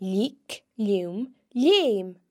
The slender L sound is made by pressing your tongue against the roof of your mouth (palate) while pronouncing the letter, and occurs when the L is before or after an e or i. The slender L can be heard in leugh (read):